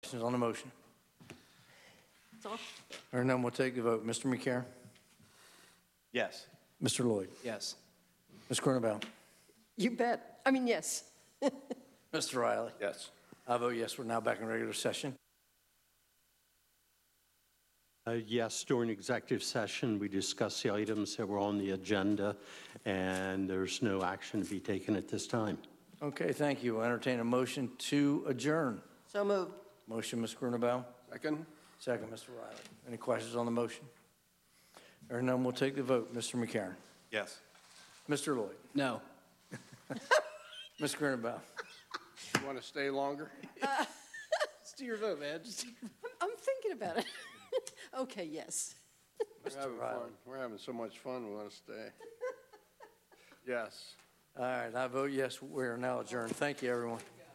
Meeting location: Council Chambers, Sussex County Administrative Office Building, 2 The Circle, Georgetown
Meeting type: County Council